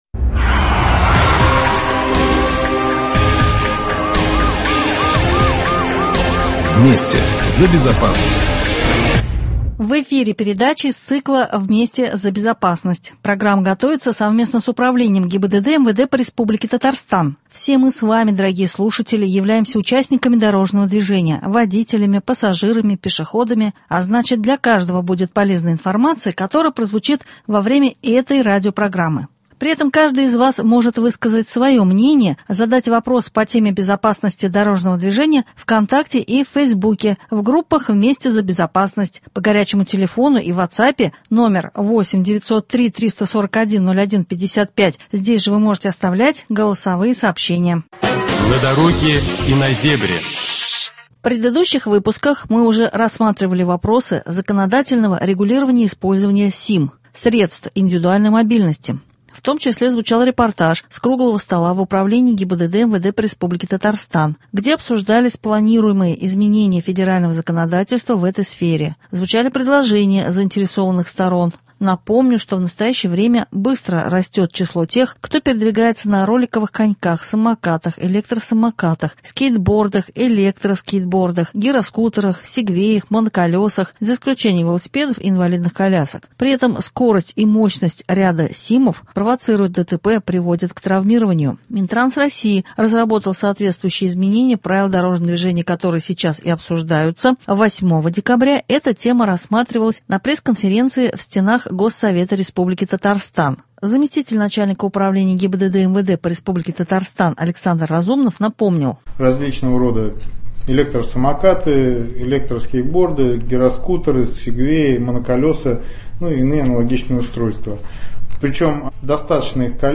8 декабря эта тема рассматривалась на пресс — конференции в стенах Госсовета Татарстана. Спикер — заместитель начальника Управления ГИБДД МВД по РТ Александр Разумнов.